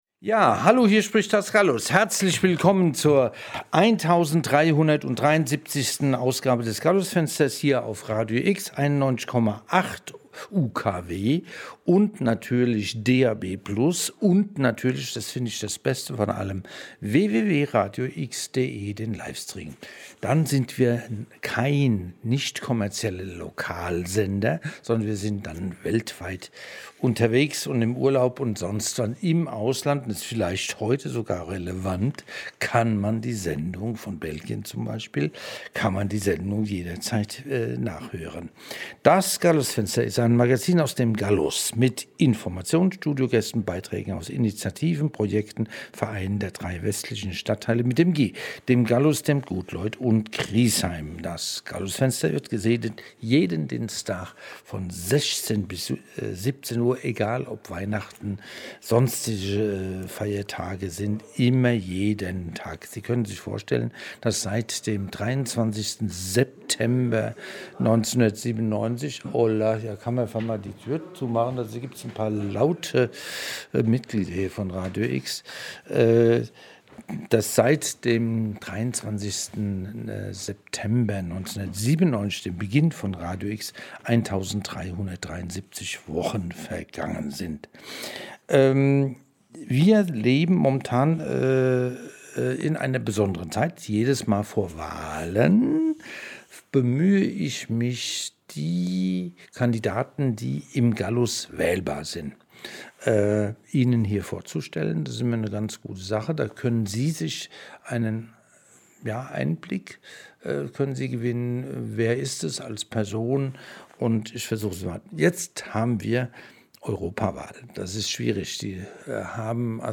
Interview mit Deborah Düring (Die Grünen) zur Bundestagswahl | radio x Sendung Gallusfenster vom 18.